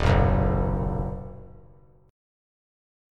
Ebm#5 chord